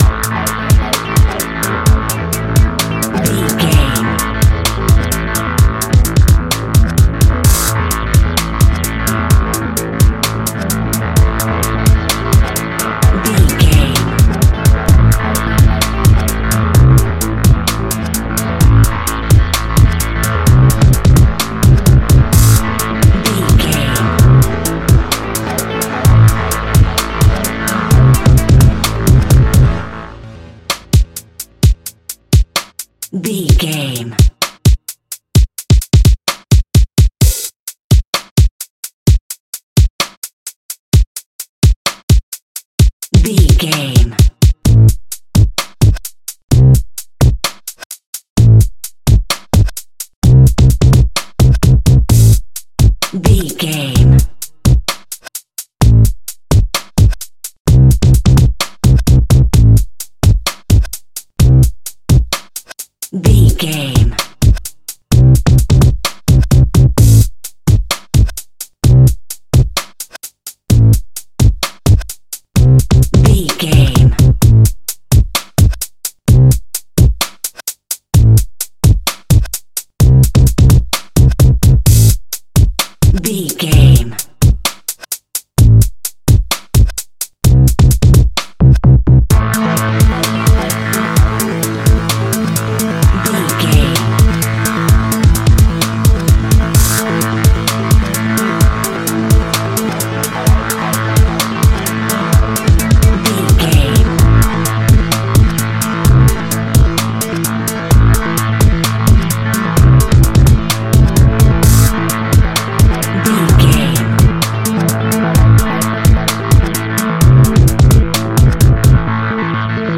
Aeolian/Minor
Fast
Funk
hip hop
drums
bass guitar
electric guitar
piano
hammond organ
percussion